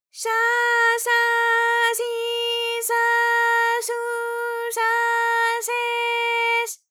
ALYS-DB-001-JPN - First Japanese UTAU vocal library of ALYS.
sha_sha_shi_sha_shu_sha_she_sh.wav